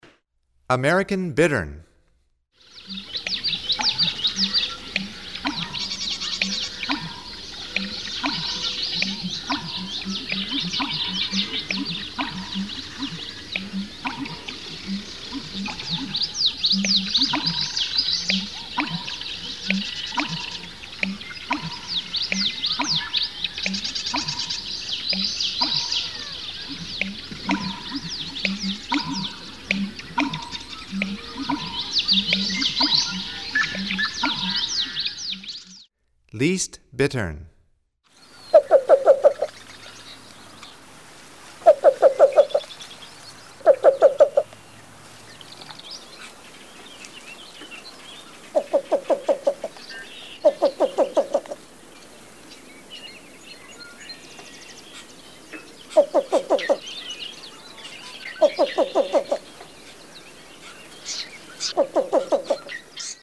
Here is the sounds of an American Bittern and a Least Bittern from Stokes Bird Songs
22-american-least-bittern-bird-songs-western-01.mp3